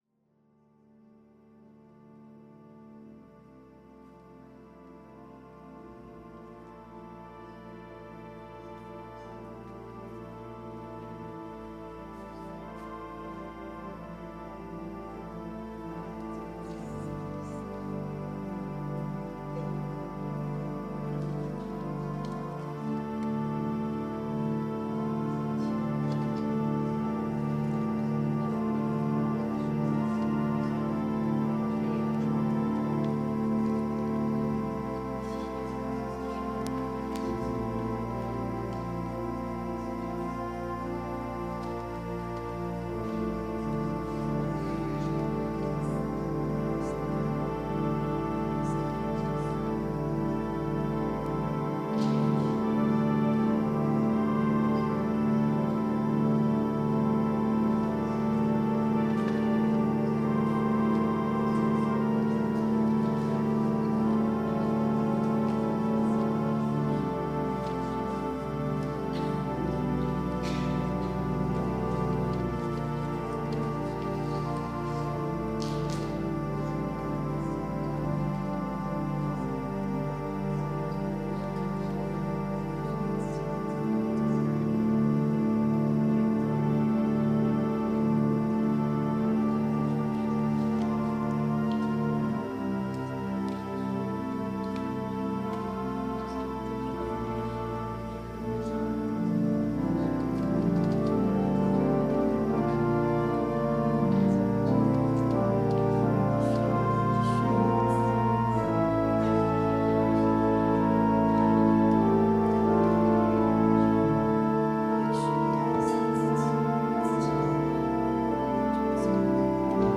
Podcast from Christ Church Cathedral Fredericton
WORSHIP - 4:00 p.m. Fourth Sunday in Lent